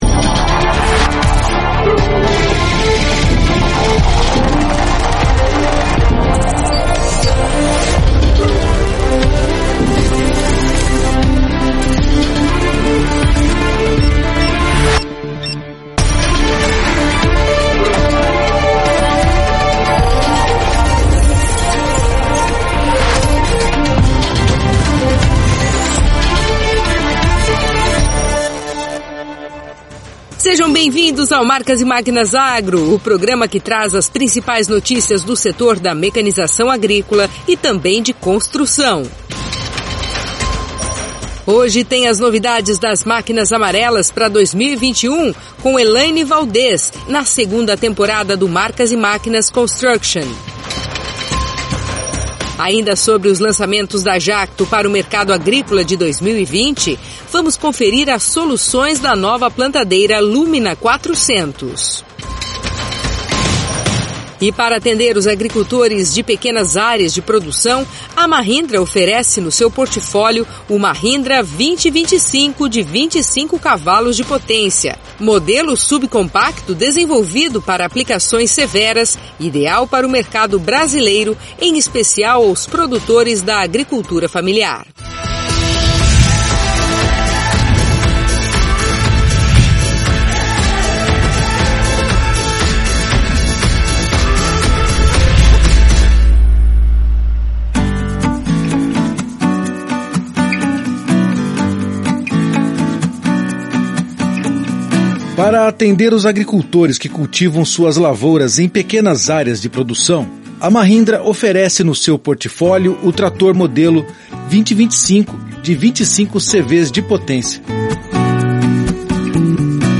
Nesta edição tem um bate papo exclusivo sobre perspectivas e novidades do mercado para a segunda temporada do Marcas e Máquinas Construction.